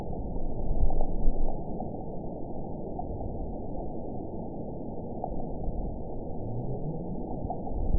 event 922105 date 12/26/24 time 11:50:44 GMT (11 months, 1 week ago) score 9.36 location TSS-AB03 detected by nrw target species NRW annotations +NRW Spectrogram: Frequency (kHz) vs. Time (s) audio not available .wav